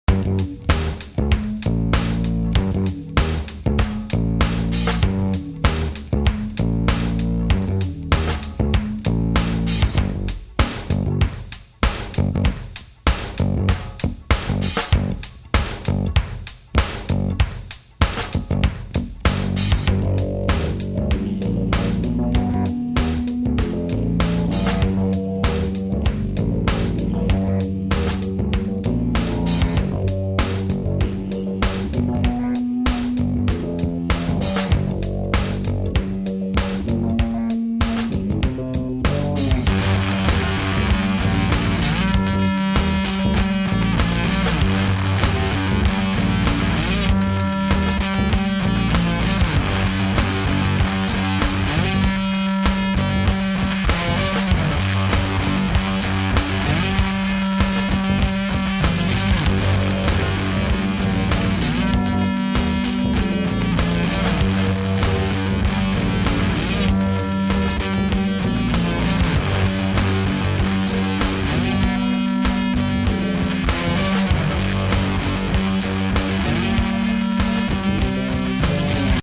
orginal 3 bass grooves